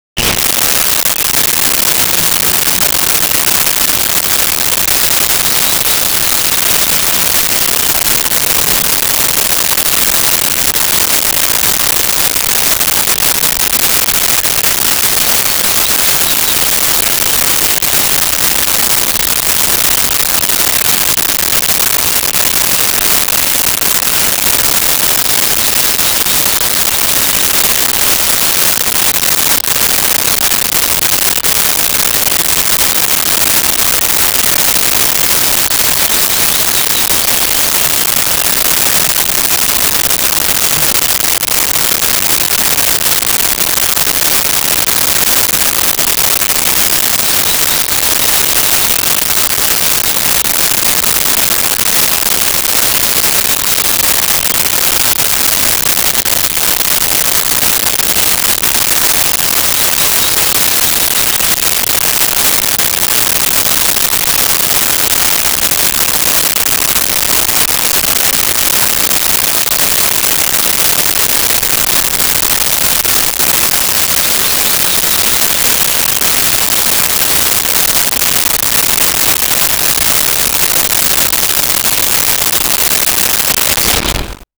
Forest Birds Insect Buzz 01
Forest Birds Insect Buzz 01.wav